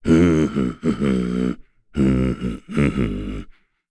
Bernheim-Vox_Hum.wav